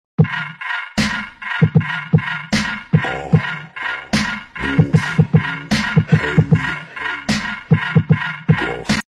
Khaby Lame Mechanism sound effect
u3-Khaby-Lame-Mechanism-sound-effect.mp3